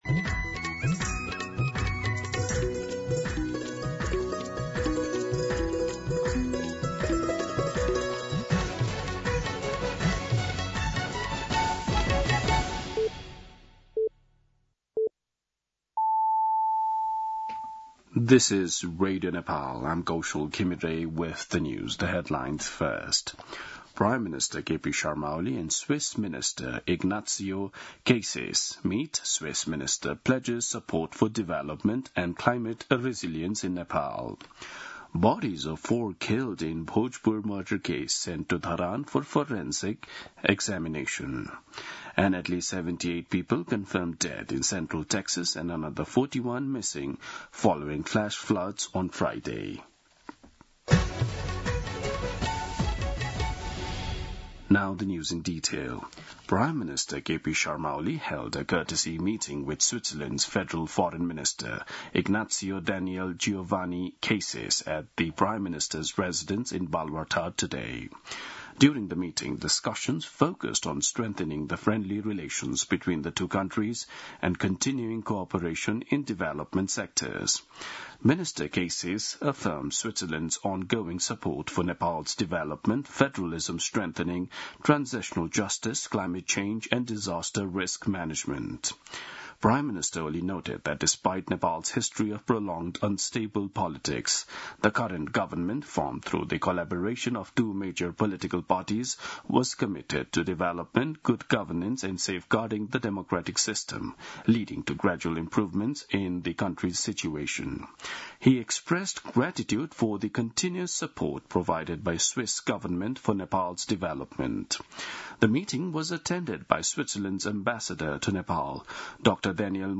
दिउँसो २ बजेको अङ्ग्रेजी समाचार : २३ असार , २०८२
2-pm-English-News-3-23.mp3